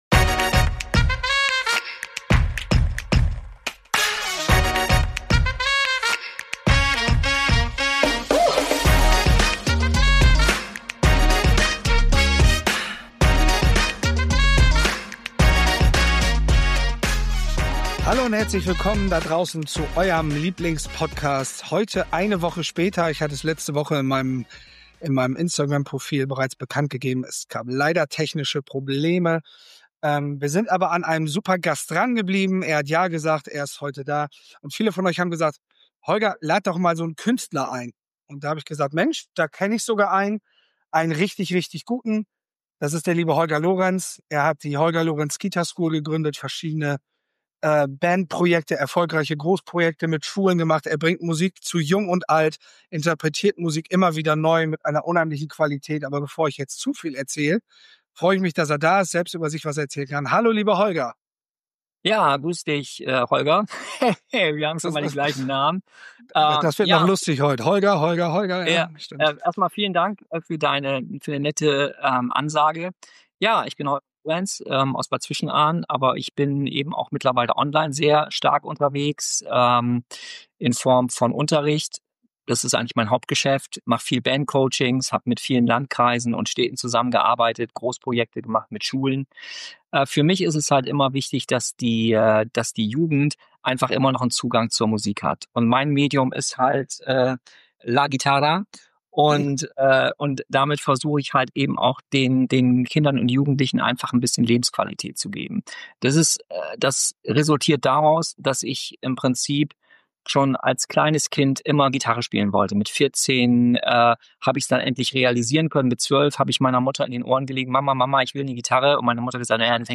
Musik als Schule des Lebens Warum Fehler kein Makel sind – Interview